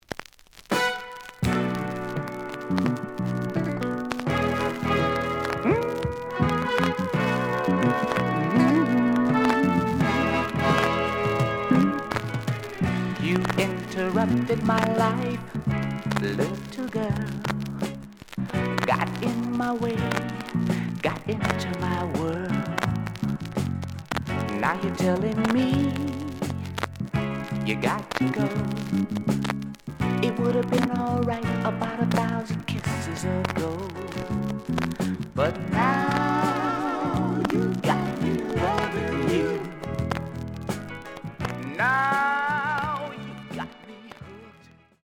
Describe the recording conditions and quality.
The audio sample is recorded from the actual item. Some click noise on both sides due to scratches.